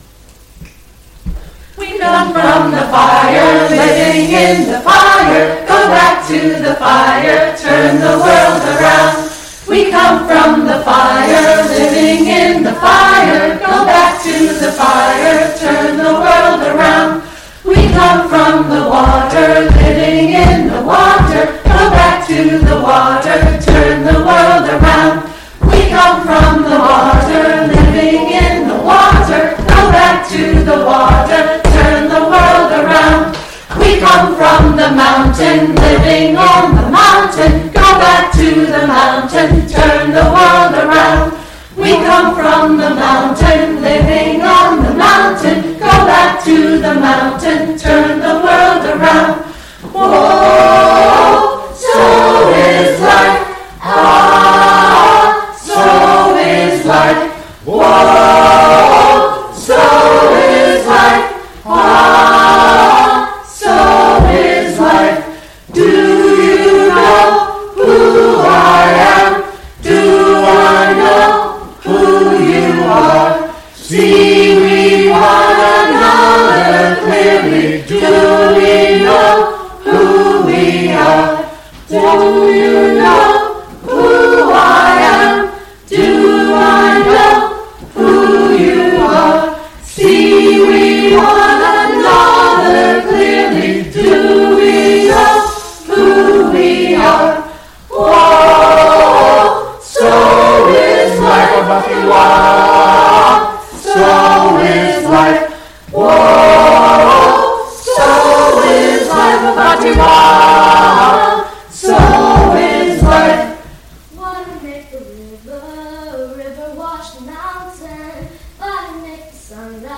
This sermon captures a religious service dedicated to honoring the environment through a blend of song, prayer, and storytelling.
The ceremony emphasizes a spiritual connection to the natural world, using guided meditation to help participants feel rooted in the earth and interconnected with one another. A central feature of the gathering is a reading based on Chief Seattle’s message, which highlights the sanctity of the land and warns against the destruction of the wilderness. Through various hymns and rituals, the congregation expresses a covenant of love and a commitment to preserving the p